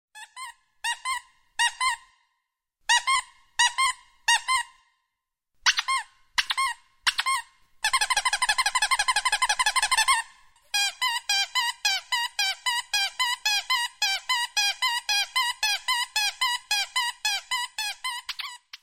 squeaky-toy_20956.mp3